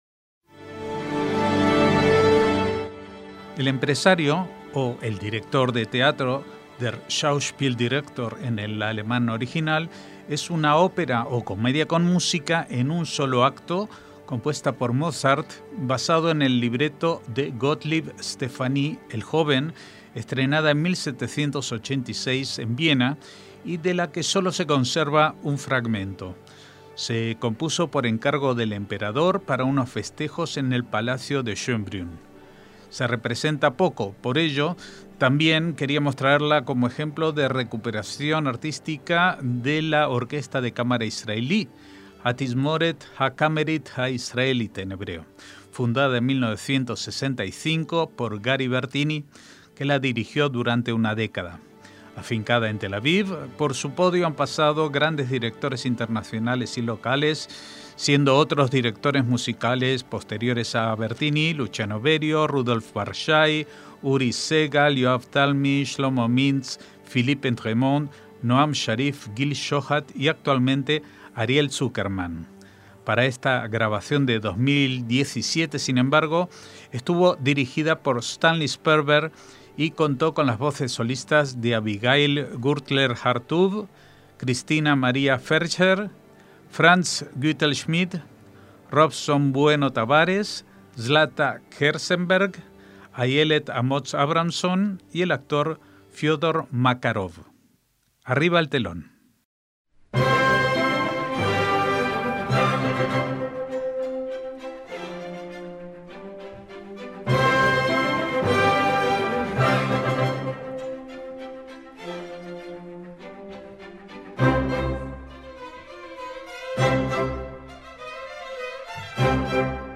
una ópera o comedia con música en un solo acto